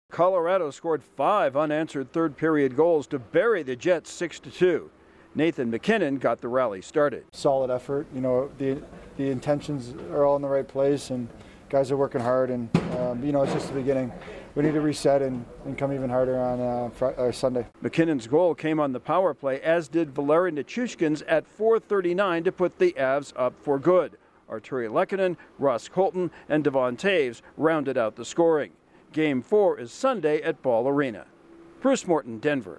The Avalanche erupt in the final period to whip the Jets. Correspondent